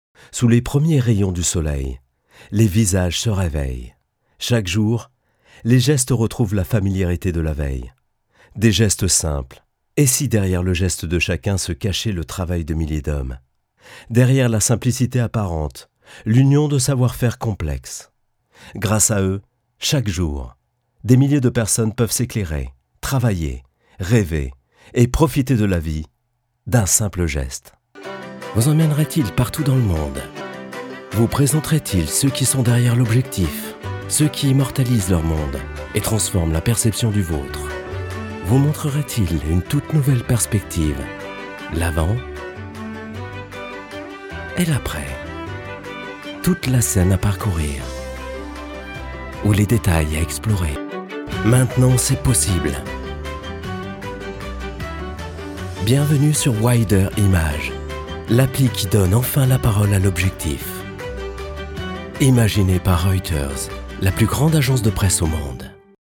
Male
Assured, Character, Confident, Conversational, Cool, Corporate, Deep, Engaging, Friendly, Gravitas, Natural, Reassuring, Sarcastic, Smooth, Soft, Versatile, Warm
commercial_French.mp3
Microphone: Neumann TLM103